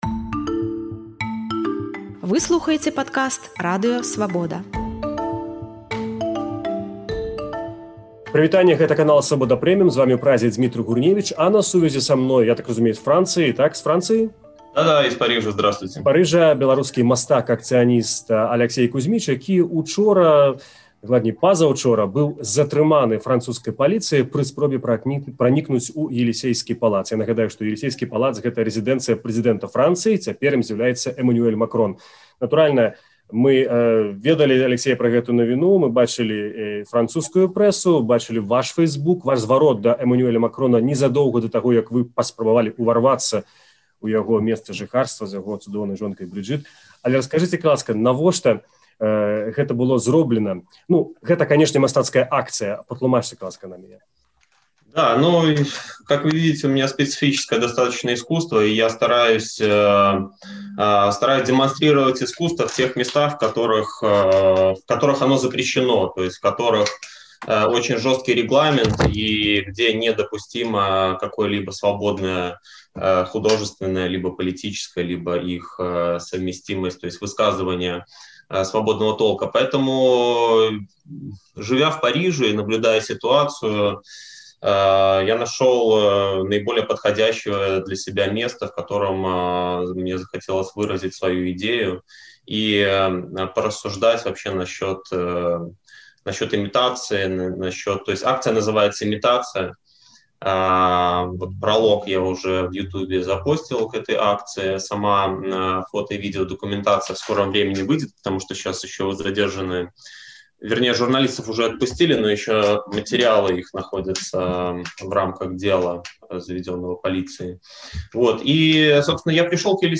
У інтэрвію «Свабодзе» мастак расказаў, як праходзіў пэрформанс і які яго сэнс.